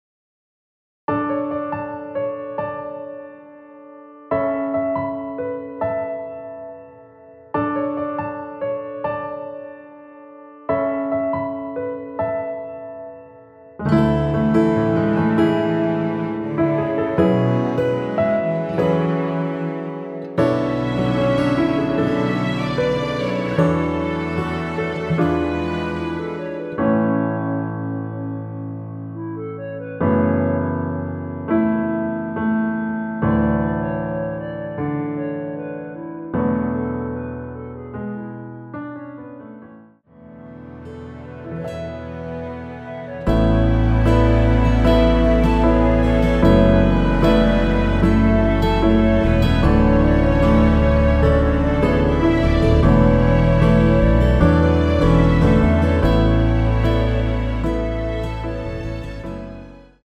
원키에서(-4)내린 멜로디 포함된 MR입니다.(미리듣기 확인)
앞부분30초, 뒷부분30초씩 편집해서 올려 드리고 있습니다.
중간에 음이 끈어지고 다시 나오는 이유는